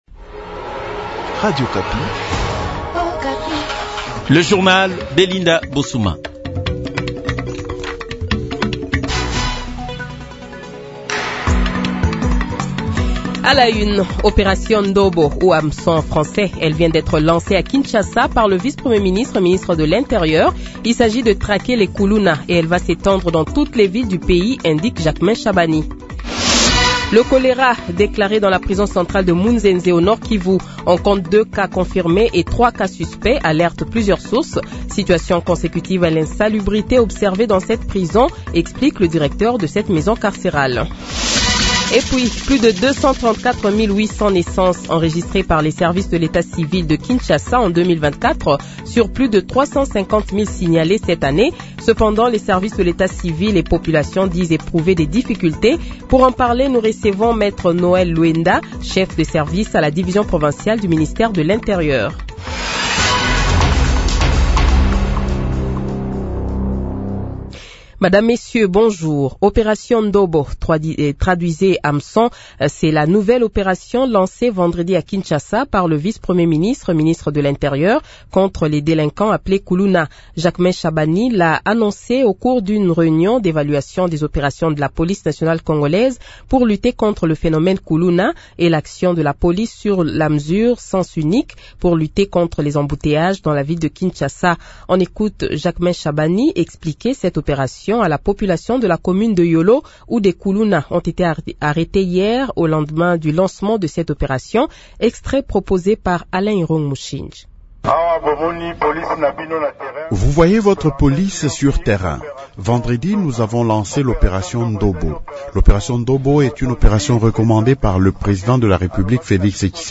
Journal Francais Midi
Le Journal de 12h, 08 Decembre 2024 :